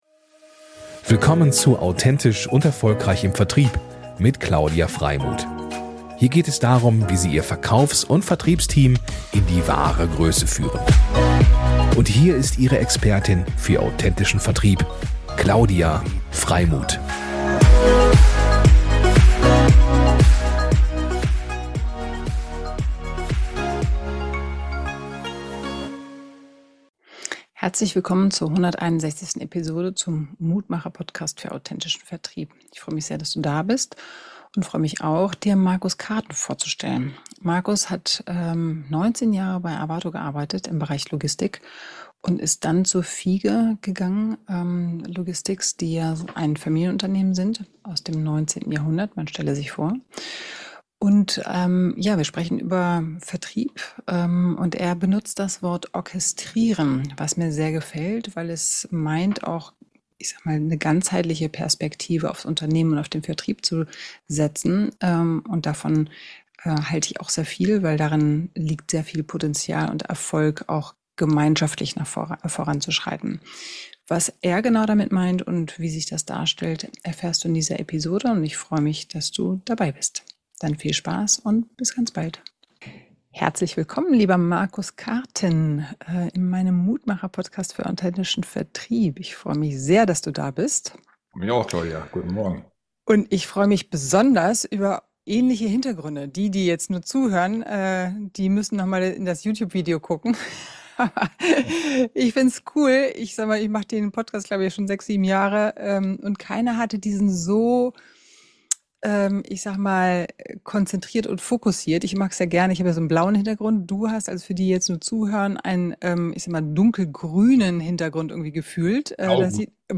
Besonders spannend ist sein Blick auf die Rolle des modernen Vertriebs: weg vom Einzelkämpfer, hin zu einem „Company Team Sell“, bei dem alle Abteilungen gemeinsam Verantwortung für den Kundenerfolg tragen. Ein Gespräch voller Klarheit, wertvoller Impulse und praxisnaher Denkanstöße für alle, die Vertrieb heute neu denken wollen.